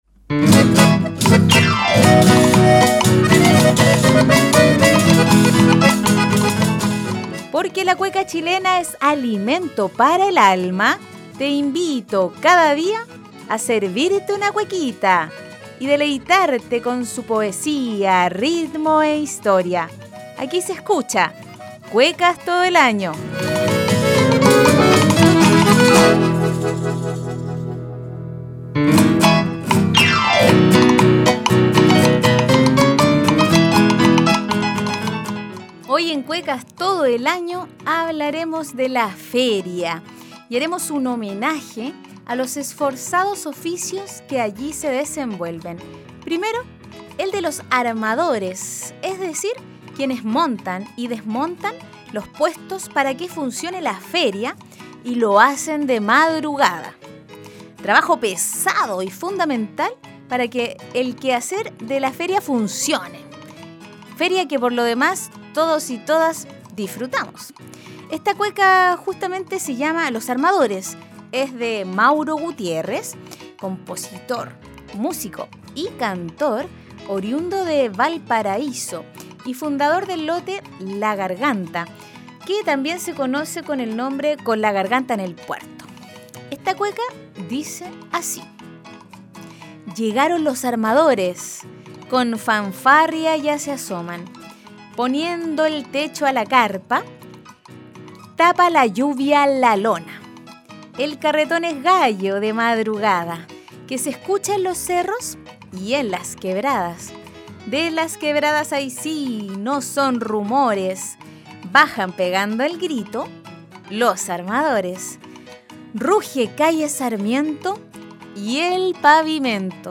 Además de apreciar su poesía y conocer un poco más de su contexto de creación, la escucharemos en la voz de sus creadores.